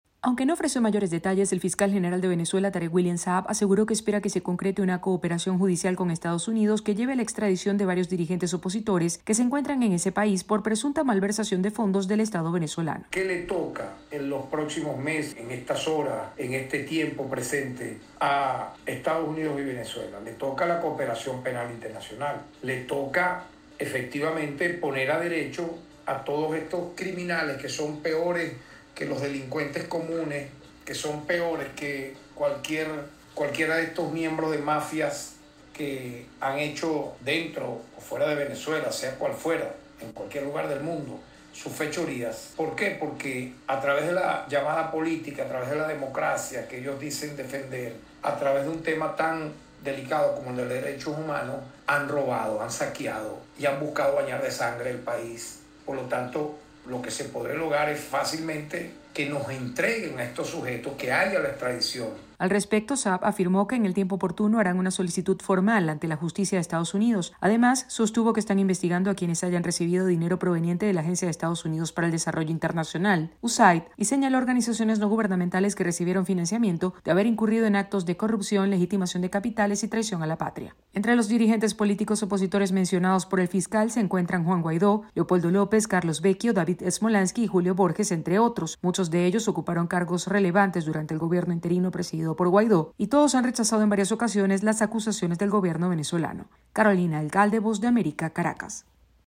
AudioNoticias
La Fiscalía venezolana asegura que solicitará a la justicia estadounidense que dirigentes opositores sean extraditados por presunta malversación de fondos. Desde Caracas